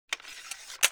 combatDroneInstall.wav